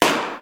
se_balloon.wav